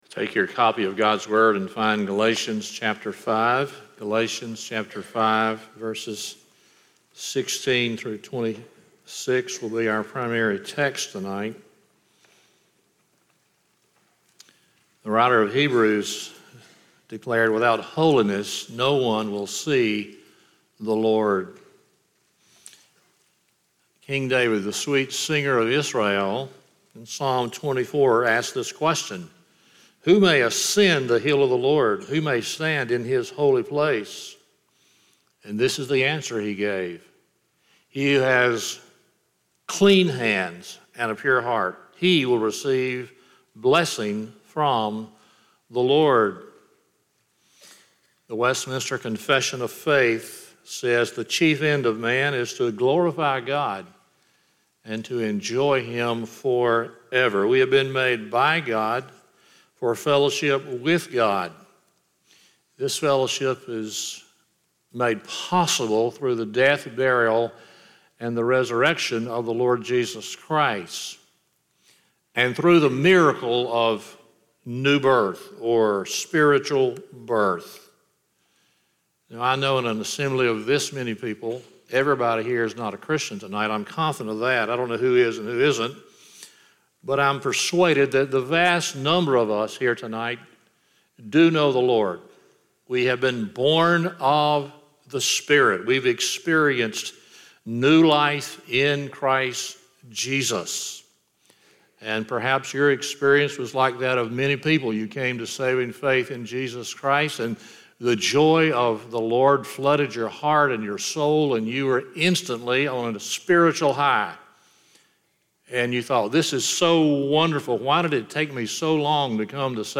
Galatians 5:16-26 Service Type: Sunday Evening 1.